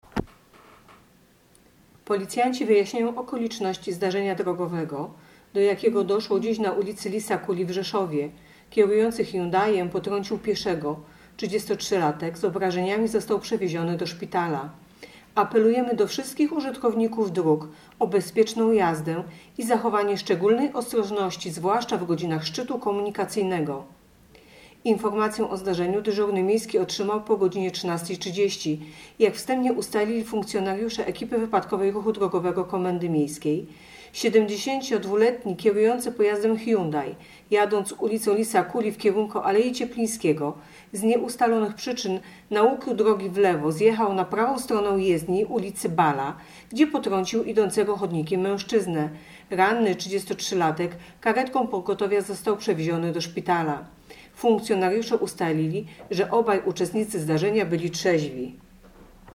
Opis nagrania: Informacja pt. Potrącenie pieszego na ul. Lisa Kuli w Rzeszowie.